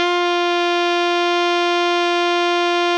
ALTO  MF F 3.wav